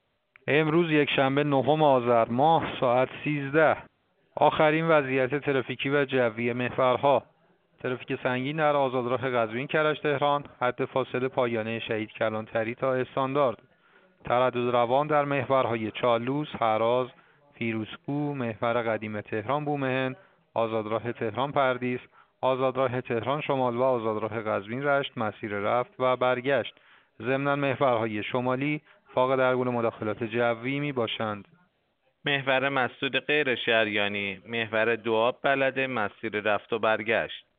گزارش رادیو اینترنتی از آخرین وضعیت ترافیکی جاده‌ها ساعت ۱۳ نهم آذر؛